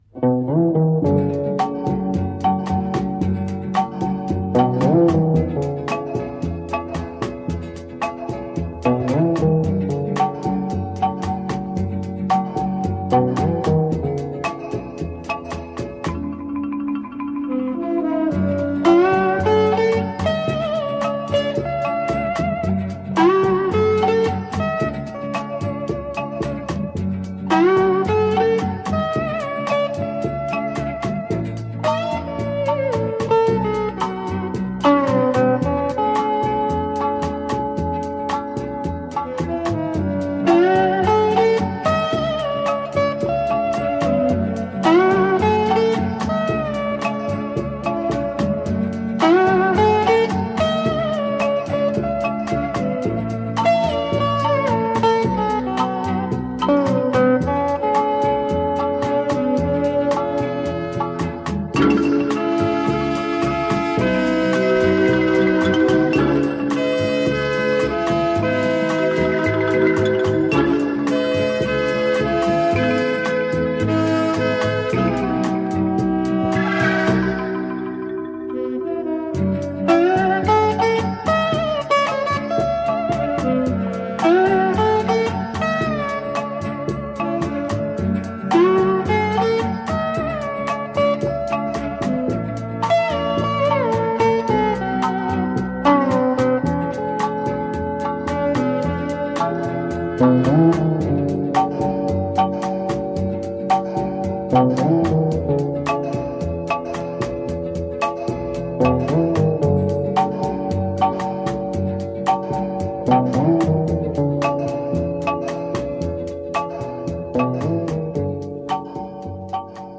radiomarelamaddalena / STRUMENTALE / GUITAR HAWAY / 1 /